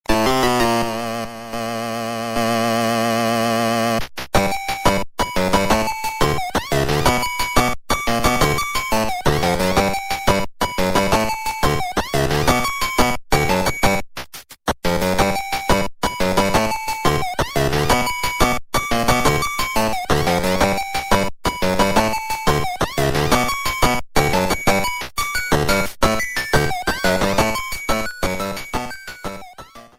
Self-recorded